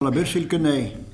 Localisation Foussais-Payré
Catégorie Locution